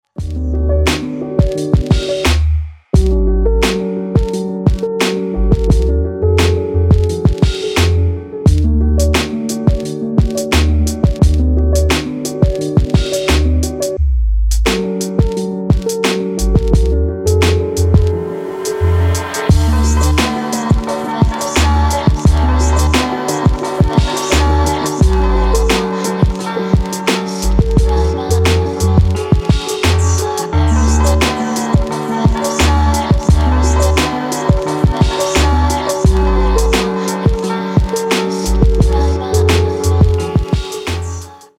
• Качество: 320, Stereo
мелодичные
спокойные
пианино
расслабляющие
Красивая трендовая мелодия из Тик Тока